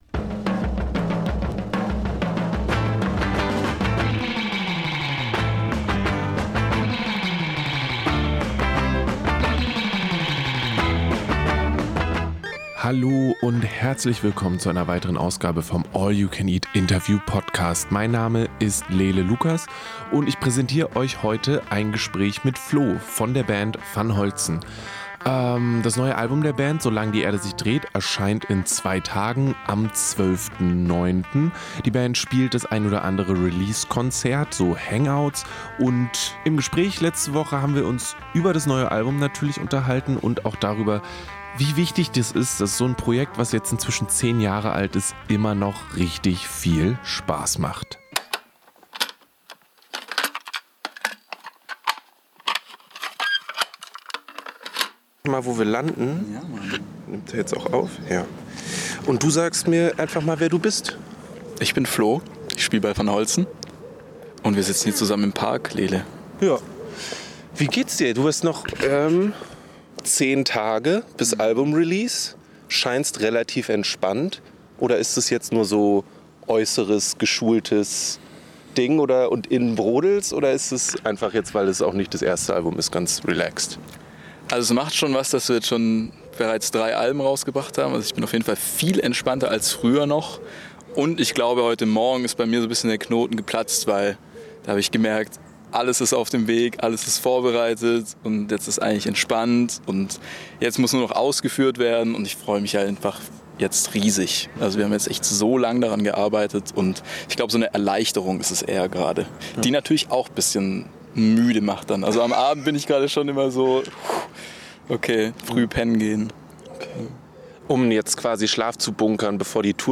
Der Brauch – Ein Interview mit The Hirsch Effekt